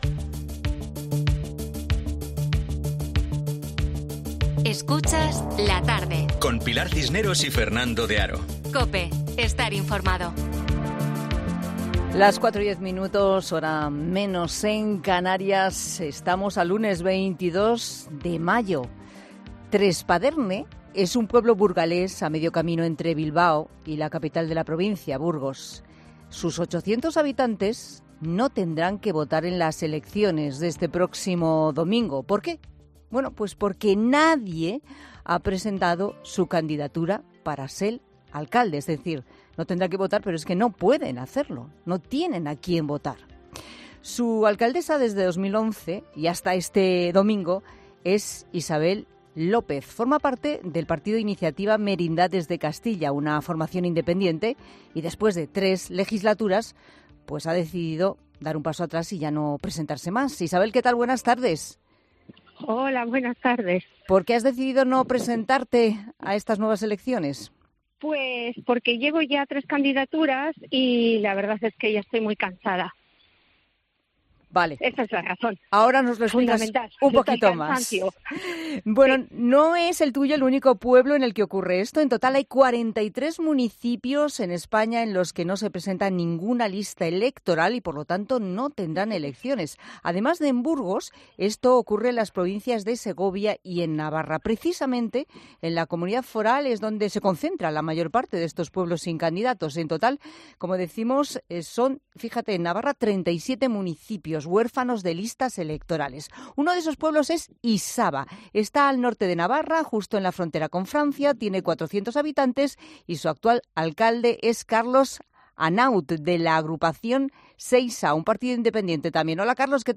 Dos alcaldes salientes de los pueblos sin listas electorales, alzan la voz en La Tarde por la pasividad de los jóvenes